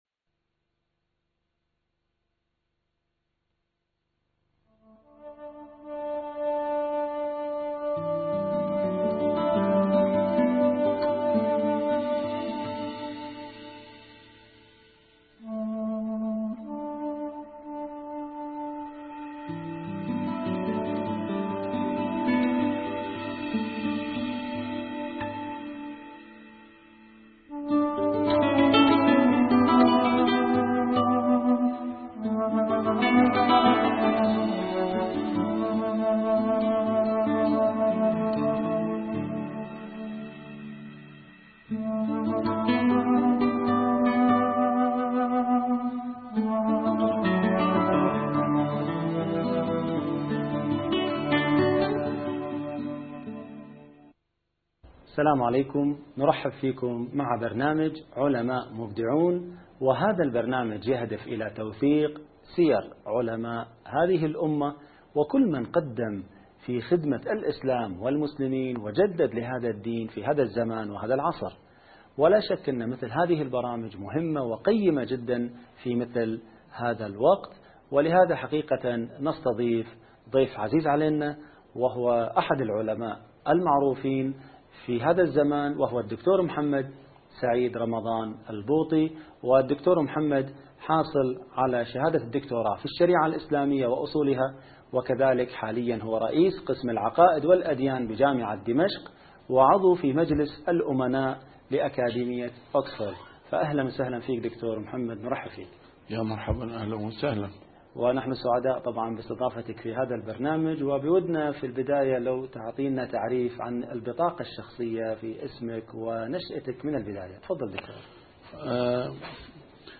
نسيم الشام › A MARTYR SCHOLAR: IMAM MUHAMMAD SAEED RAMADAN AL-BOUTI - الدروس العلمية - محاضرات متفرقة في مناسبات مختلفة - الإمام الشهيد البوطي ومشاهد من حياته | مقابلة
محاضرات متفرقة في مناسبات مختلفة - A MARTYR SCHOLAR: IMAM MUHAMMAD SAEED RAMADAN AL-BOUTI - الدروس العلمية - الإمام الشهيد البوطي ومشاهد من حياته | مقابلة